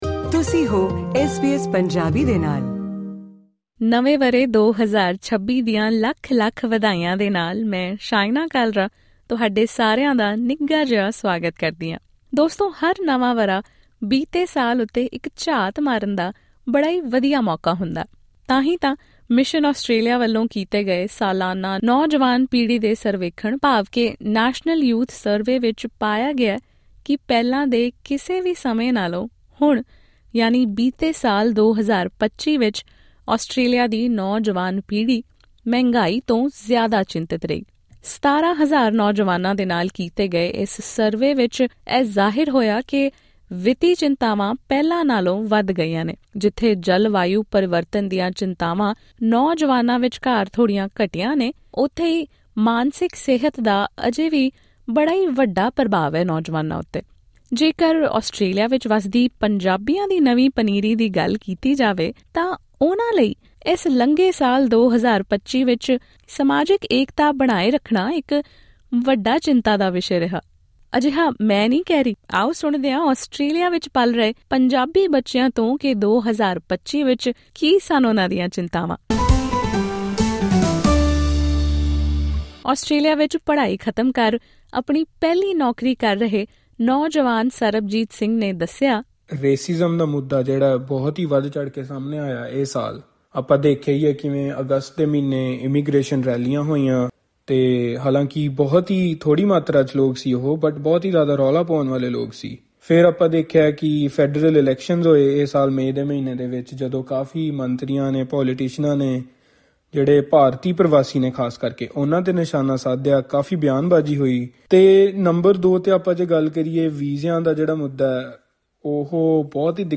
ਵਿਸ਼ਵਵਿਆਪੀ ਆਰਥਿਕ ਅਨਿਸ਼ਚਿਤਤਾ, ਤੇਜ਼ ਤਕਨੀਕੀ ਤਬਦੀਲੀਆਂ ਅਤੇ ਵਾਤਾਵਰਣ ਦੀਆਂ ਗੰਭੀਰ ਚੇਤਾਵਨੀਆਂ ਦੇ ਇਸ ਦੌਰ ਵਿੱਚ, ਆਸਟ੍ਰੇਲੀਆ ਵਿੱਚ ਪਲ ਰਹੀ ਪੰਜਾਬੀ ਨੌਜਵਾਨ ਪੀੜ੍ਹੀ ਕਿਹੜੀਆਂ ਗੱਲਾਂ ਨੂੰ ਲੈ ਕੇ ਸਭ ਤੋਂ ਵੱਧ ਚਿੰਤਤ ਹੈ? ਐਸ ਬੀ ਐਸ ਪੰਜਾਬੀ ਨੇ ਆਸਟ੍ਰੇਲੀਅਨ ਪੰਜਾਬੀ ਨੌਜਵਾਨਾਂ ਨਾਲ ਗੱਲਬਾਤ ਕਰਕੇ 2025 ਦੌਰਾਨ ਉਨ੍ਹਾਂ ਦੀਆਂ ਮੁੱਖ ਚਿੰਤਾਵਾਂ ਅਤੇ 2026 ਤੋਂ ਲਾਈਆਂ ਹੋਈਆਂ ਉਮੀਦਾਂ ਨੂੰ ਸਮਝਣ ਦੀ ਕੋਸ਼ਿਸ਼ ਕੀਤੀ ਹੈ।